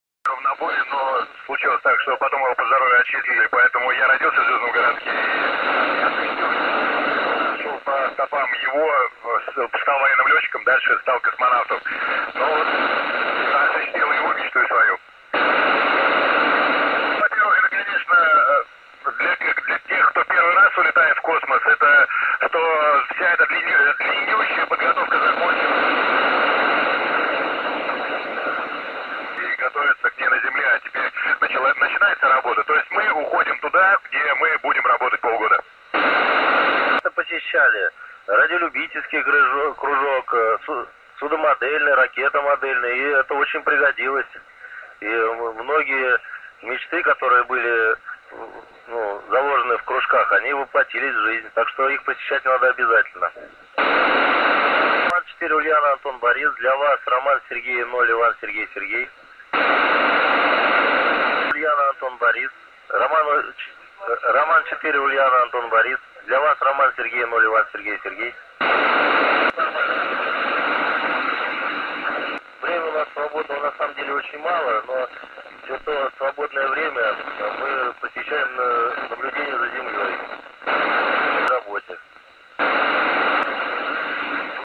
RS0ISS VOICE 30.08.2014_10.07.32_UTC 145.800